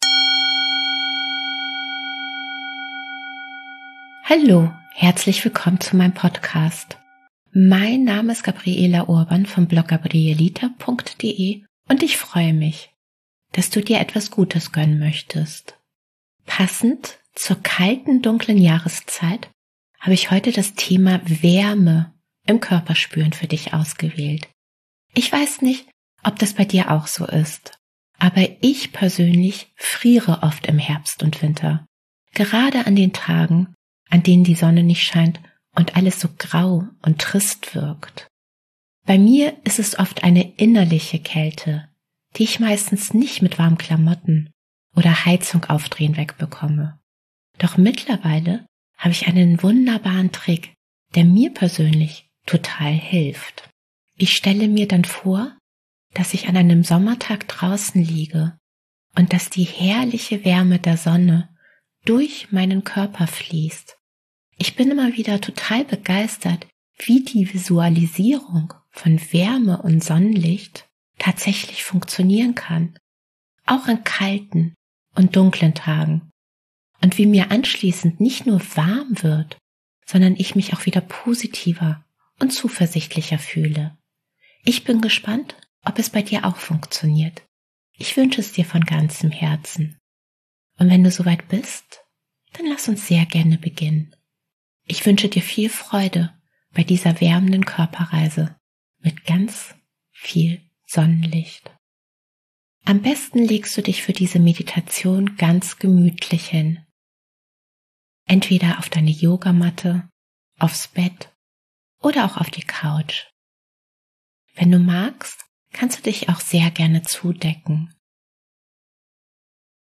#023 Meditation Wärme im Körper spüren